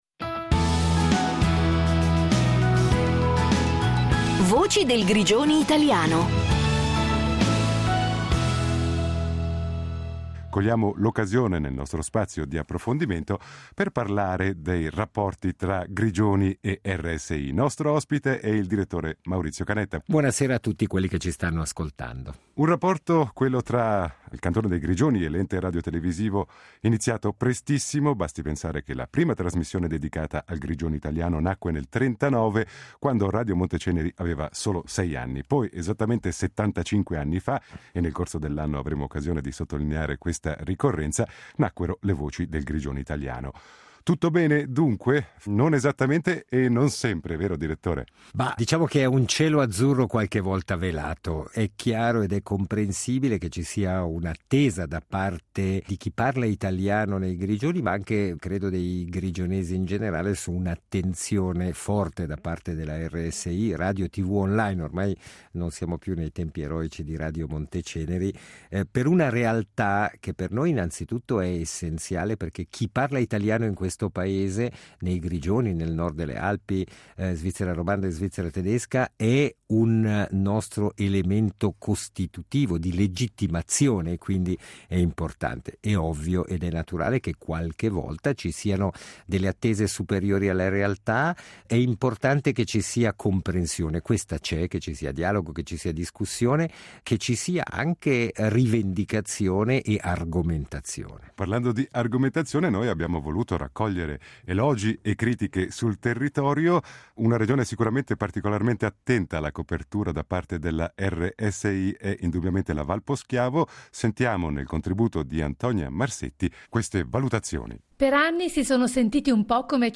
A colloquio con il Direttore Maurizio Canetta